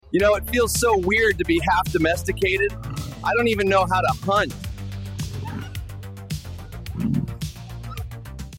Lion in a zoo.